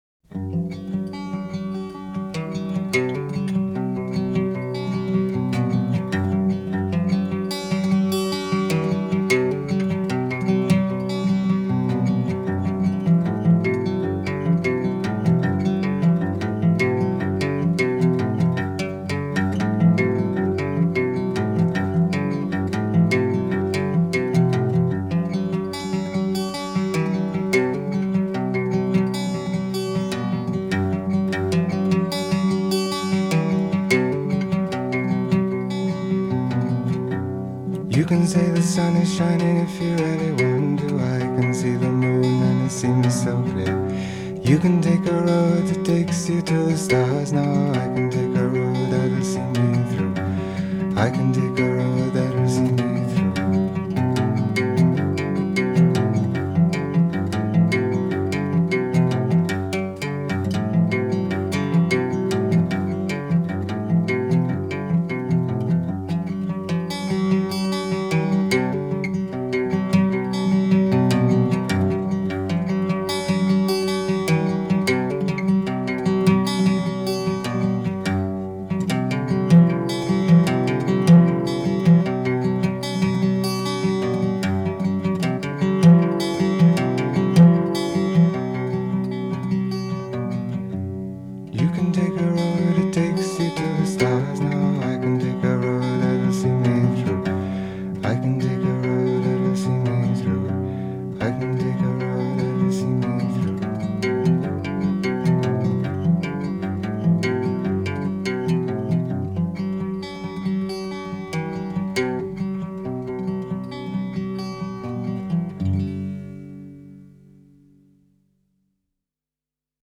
Жанр: Pop, Folk